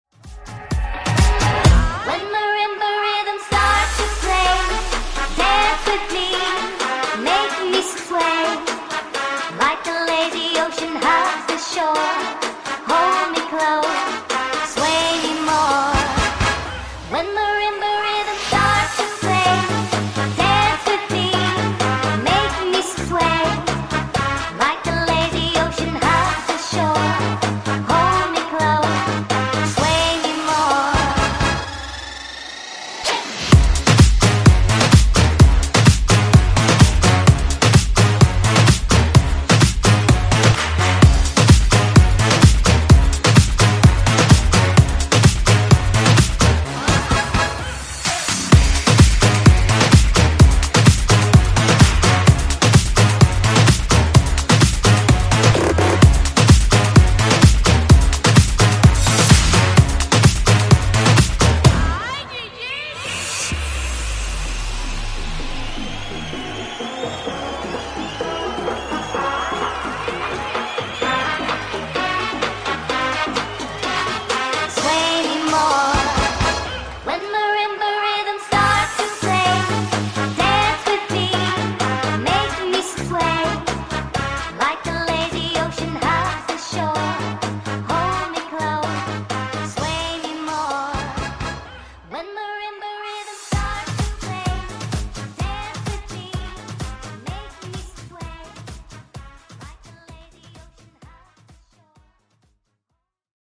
Genre: RE-DRUM Version: Dirty BPM: 120 Time